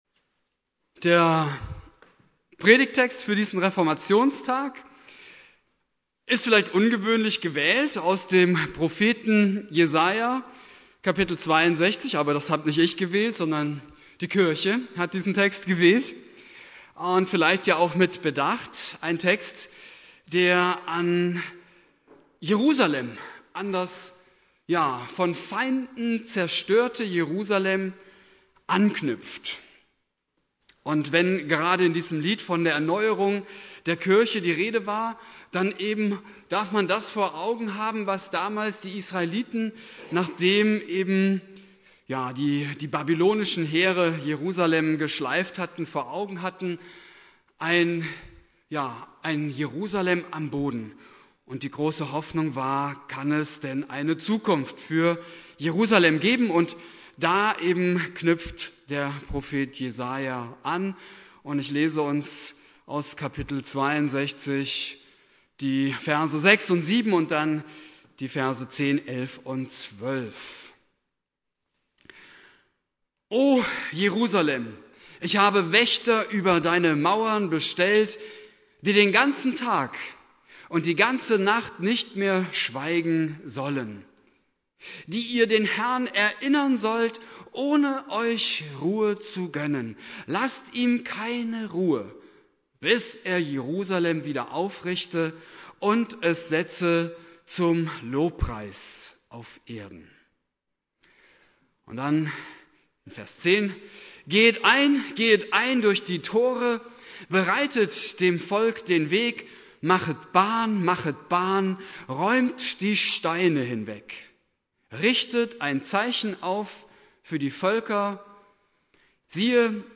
Predigt
Reformationstag Prediger